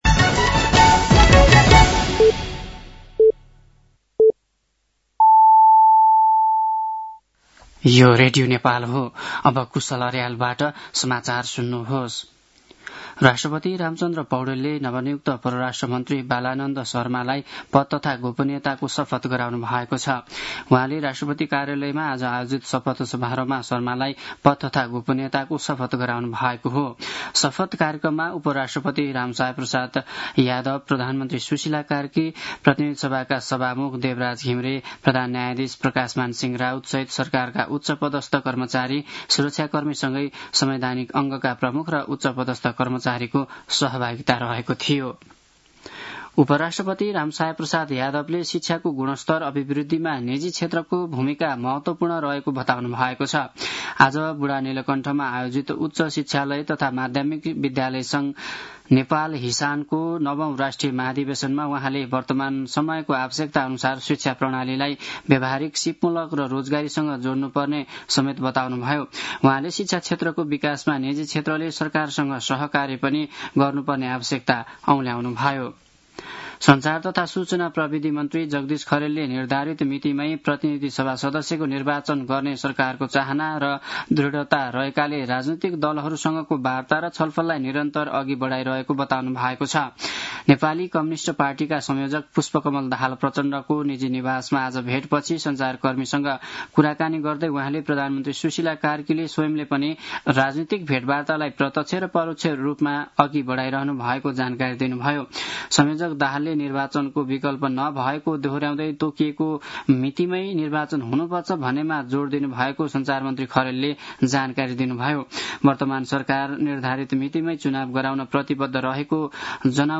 साँझ ५ बजेको नेपाली समाचार : ११ पुष , २०८२
5-pm-nepali-news-9-11.mp3